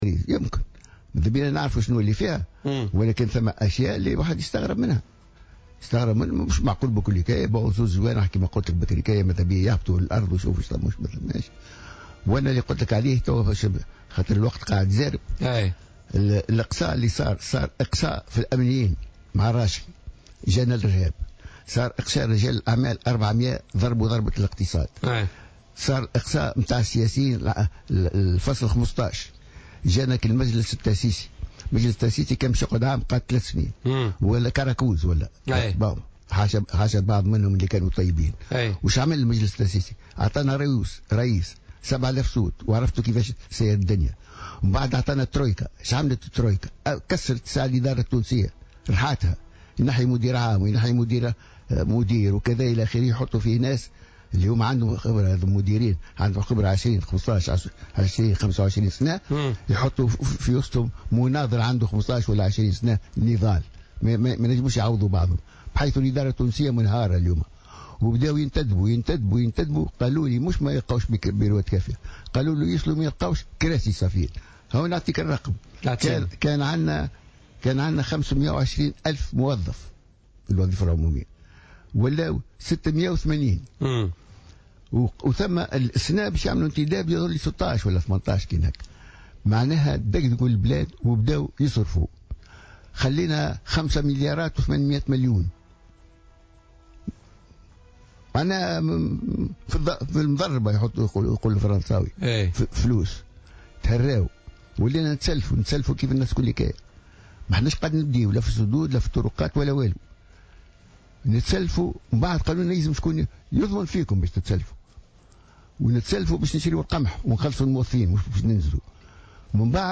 Le président du mouvement national destourien et ex-ministre de Ben Ali, Hamed Karoui était l'invité de l'émission Politica ce lundi.